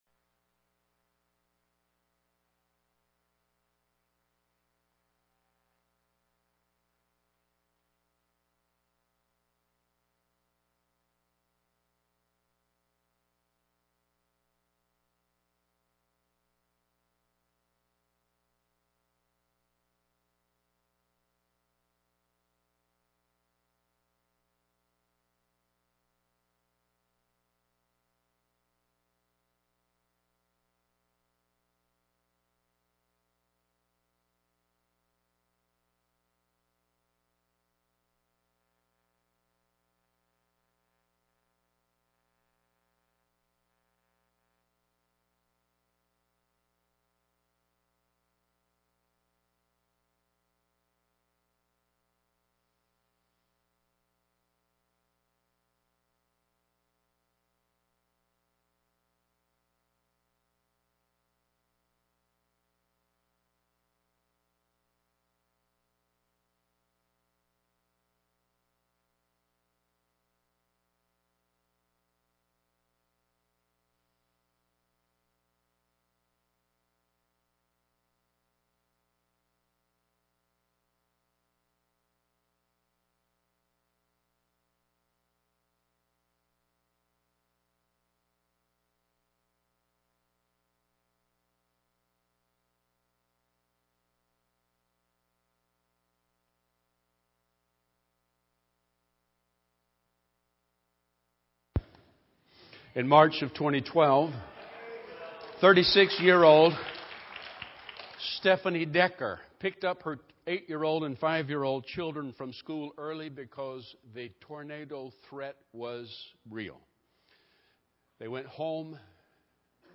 Note: the first minute or so has no audio due to a faulty microphone.